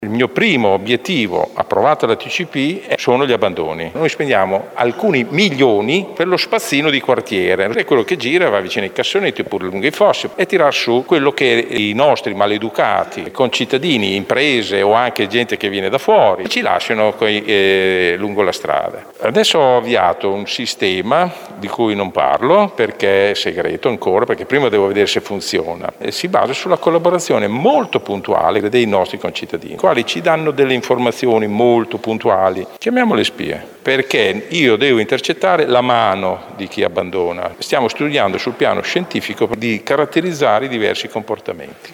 Per quanto riguarda invece i sacchi di pattume lasciati di fianco ai cassonetti in strada l’assessore all’ambiente Vittorio Molinari spiega qual è il piano: